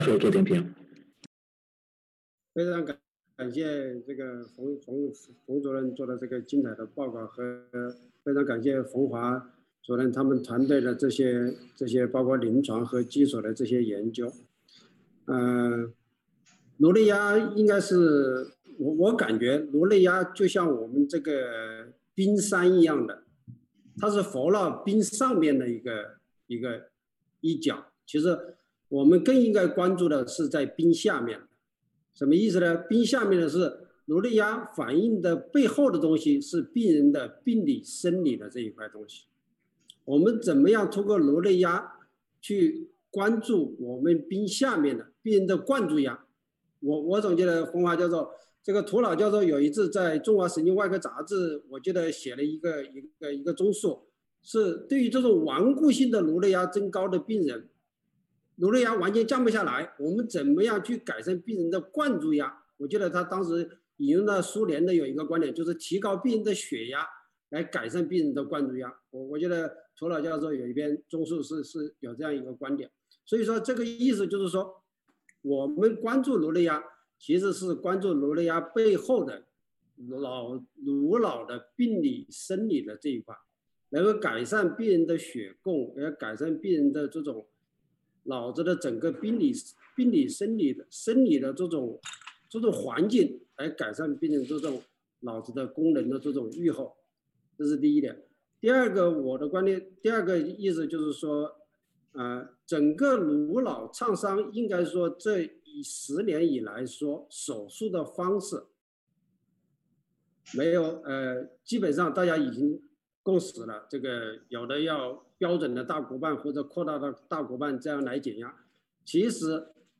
精彩点评